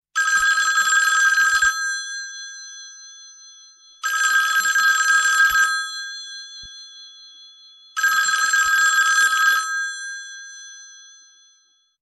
Klassisk Telefon, Klassisk, Android